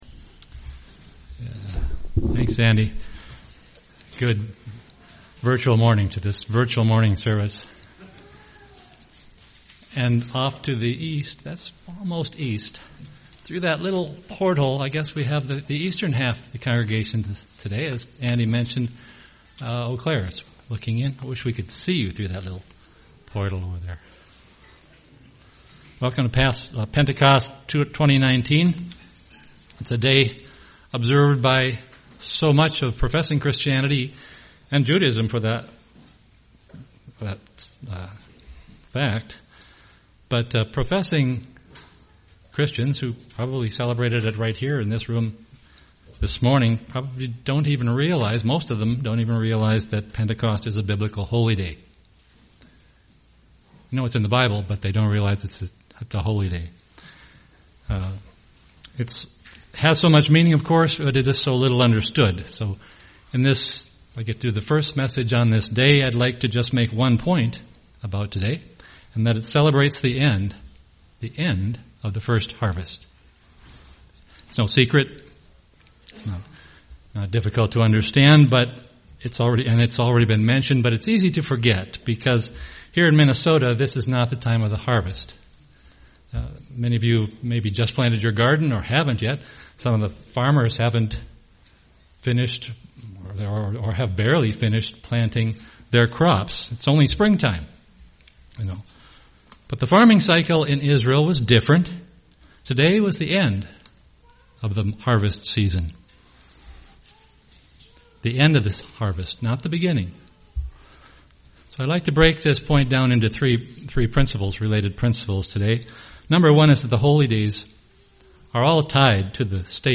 This sermonette focuses on the timing of the first month and first harvest, the fact that the Feast of Pentecost occurred at the end of the first harvest, and the implied prophetic nature of this feast.